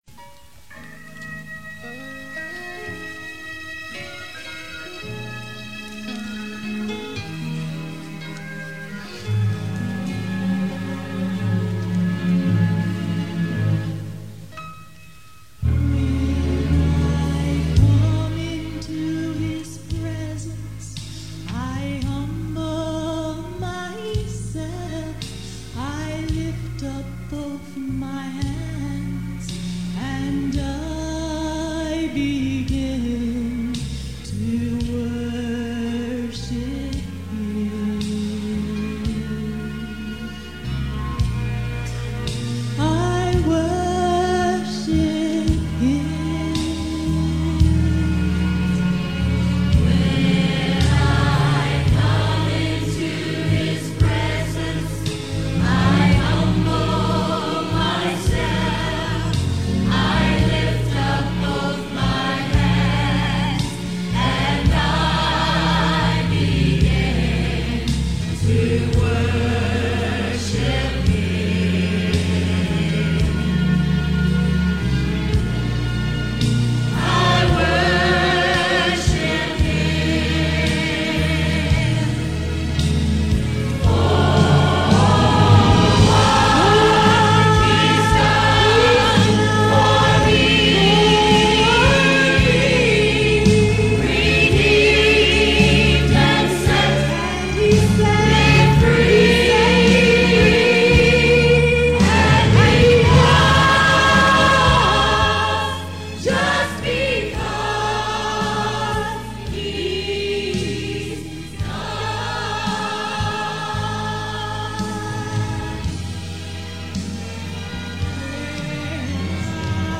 Songs from Calvary Baptist Church